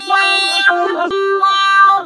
VOX FX 8  -R.wav